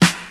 Old School Natural Snare Drum Sample F Key 55.wav
Royality free snare sample tuned to the F note. Loudest frequency: 2054Hz
old-school-natural-snare-drum-sample-f-key-55-YKj.ogg